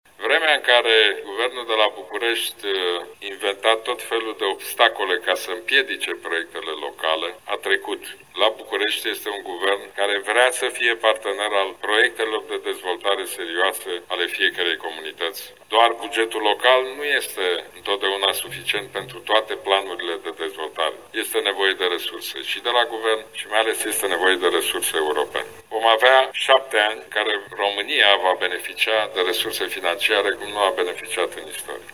Președintele PNL, Ludovic Orban, prezent aseară la un eveniment de campanie electorală la Sighișoara, a declarat că e timpul ca oamenii buni să se implice, să nu mai stea de-o parte, și a vorbit despre importanța cooperării între administrația locală și centrală.
18-sept-mures-ludovic-orban-la-Sighisoara.mp3